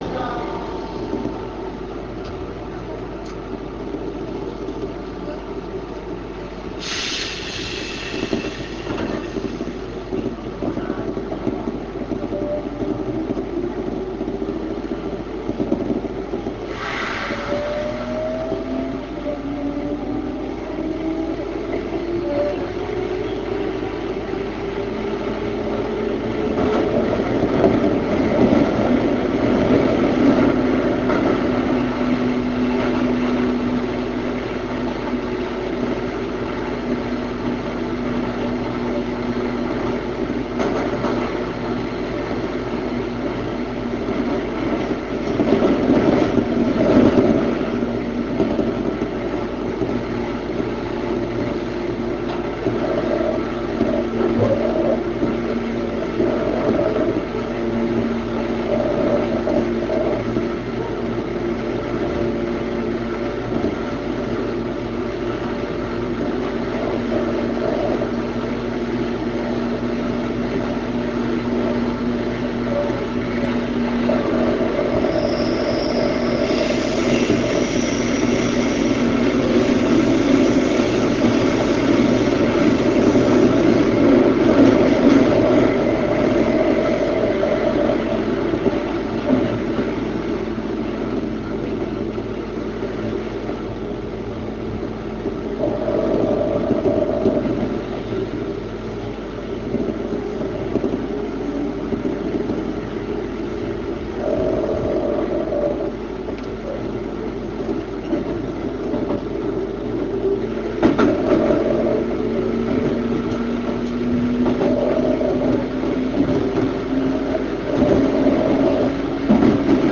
３０１系快速走行音 東陽町→浦安 RealAudio形式 854kb
３０１系快速の走行音です。速度が若干遅めなのが、ちと残念でしょうか？